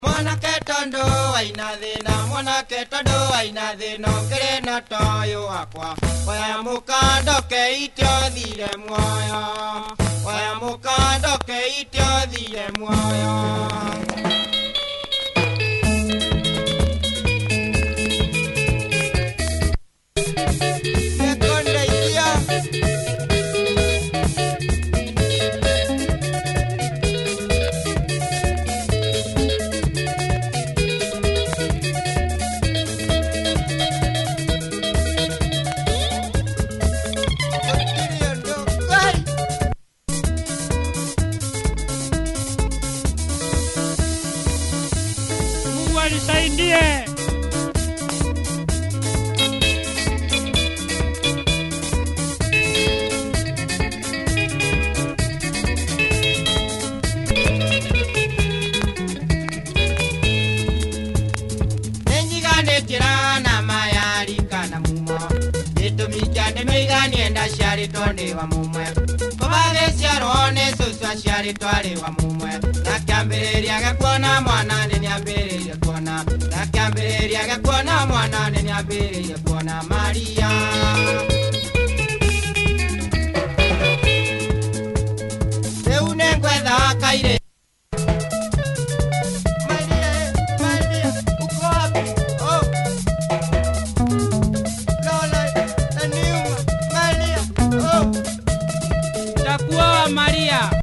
Good Kikuyu Benga